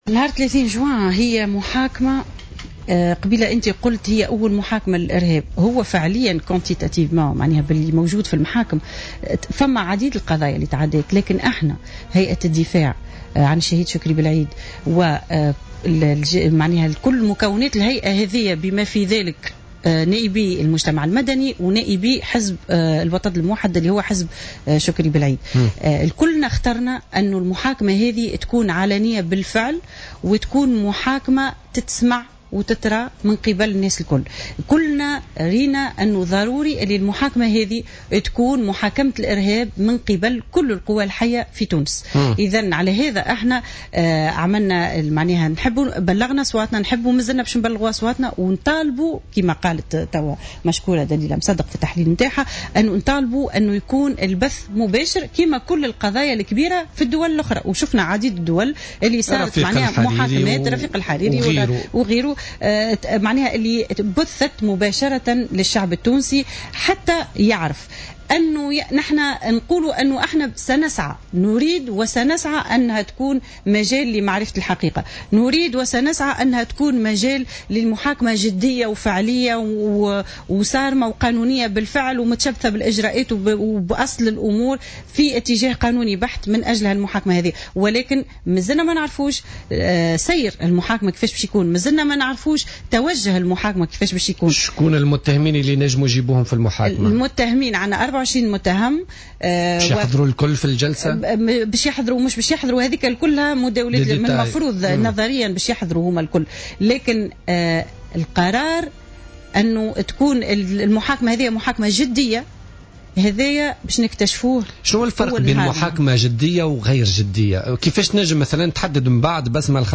دعت بسمة الخلفاوي أرملة شكري بلعيد في مداخلة لها اليوم الاربعاء في برنامج "بوليتيكا" إلى أن تكون أول جلسة للنظر في قضية اغتيال شكري بلعيد التي من المزمع عقدها يوم 30 جوان الجاري علنية ويتم بثها في المباشر.